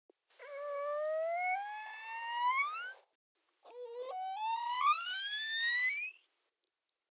Our team attaches suction-cup hydrophones directly onto each dolphin’s melon – that is, its forehead.
Signature whistles and ‘motherese’
Dolphin mothers modify their signature whistles when communicating with their calves by increasing the maximum frequency, or pitch.
Slowed recording of a bottle-nosed dolphin without her calf, then with her calf.
motherese-f123-without-and-with-calf-slowed-8-times.mp3